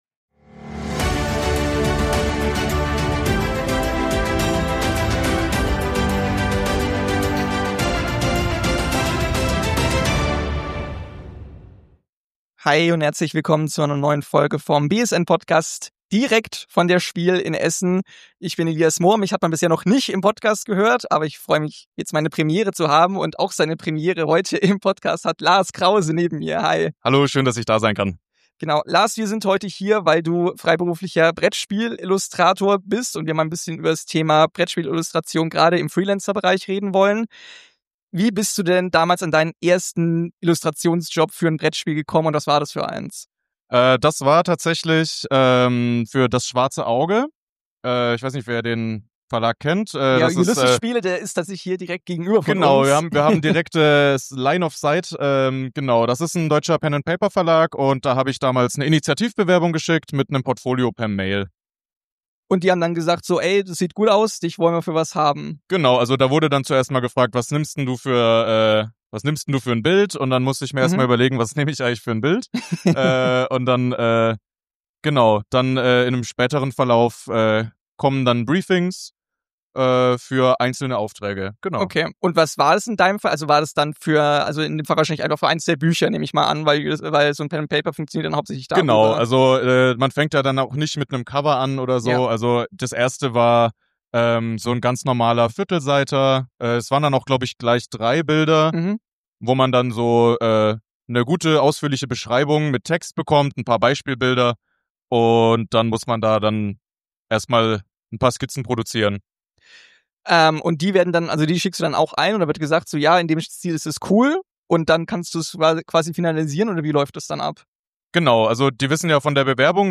Direkt von der SPIEL in Essen berichtet eine aktuelle BSN-Podcast-Folge aus erster Hand vom Arbeitsalltag eines Brettspiel-Illustrators.